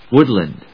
音節wood・land 発音記号・読み方
/wˈʊdlənd(米国英語)/